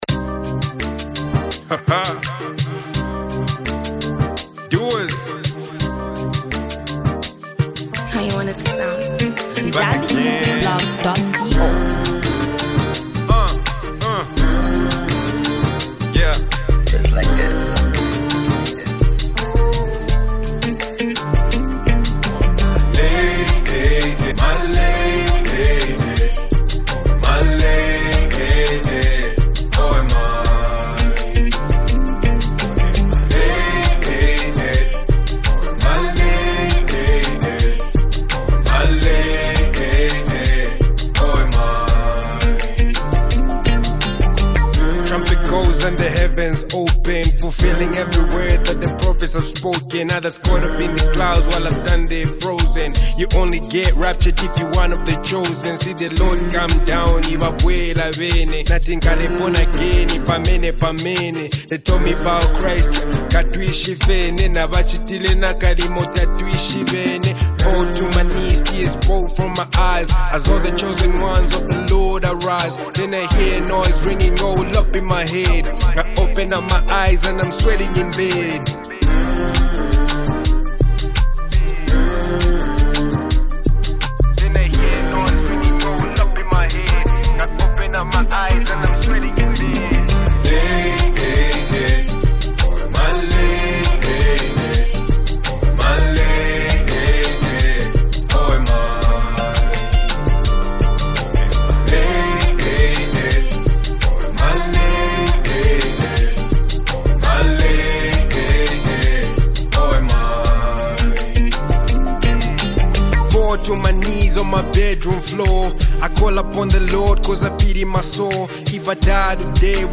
a soulful and reflective track
crafting a mellow yet striking beat